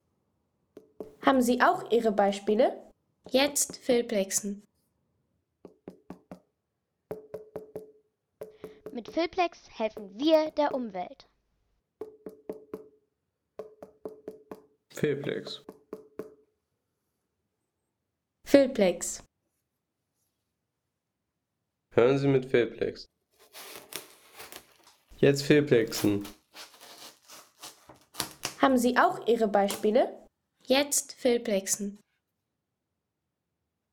Das Klopfen und Schneiden einer Wassermelone.